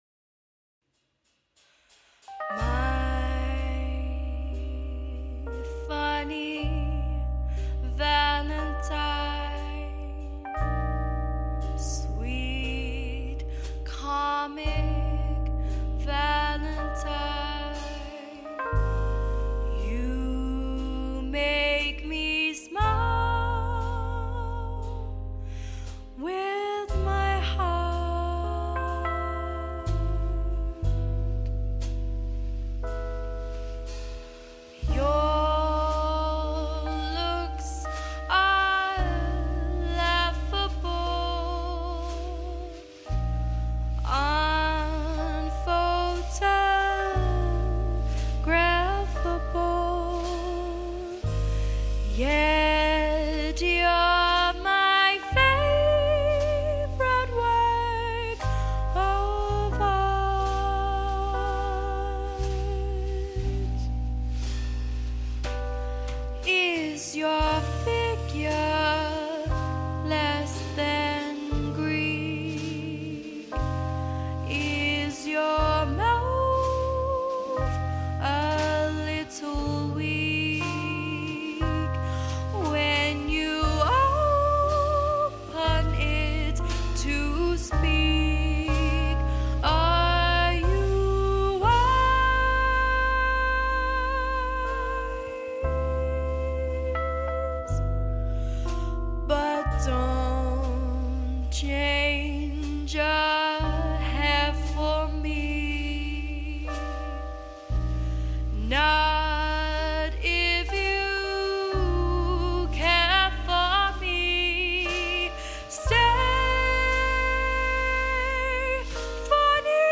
Female Vocals
Sax, Bass, Drums, Female Vocals